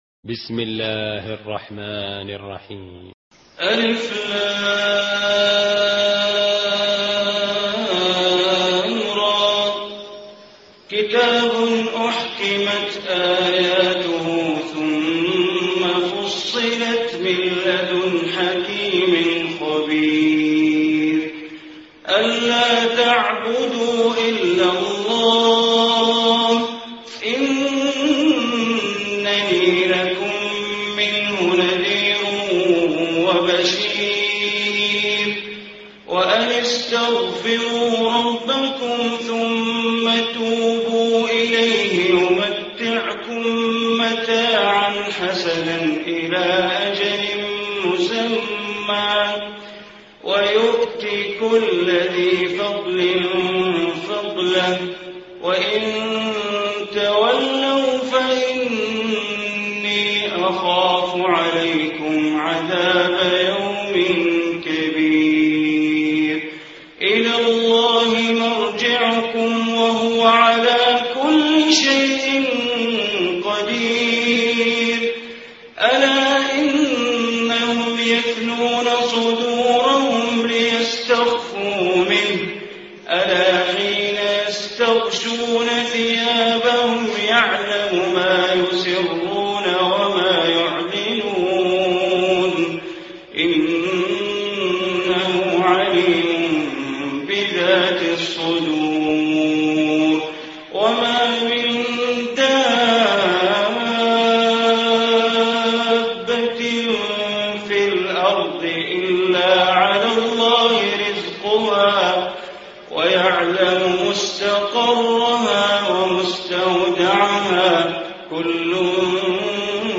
Surah Hud Recitation by Sheikh Bandar Baleela
Surah Hud, listen online mp3 tilawat / recitation in Arabic in the beautiful voice of Sheikh Bandar Baleela.